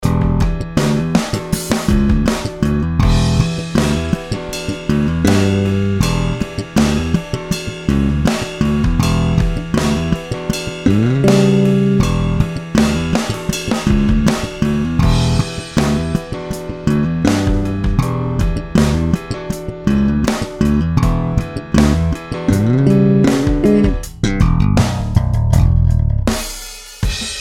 Jen tak pro porovnání jsem to samé nahrál na Fender JB 76 a Sandberg TM2, samozřejmě v pasivu a na singly:
Sandberg California TM2 (pasiv 2xJB)
JB 76 je IMHO takový tenčí a sušší, Sandberg zase takový více pod dekou a méně hravý.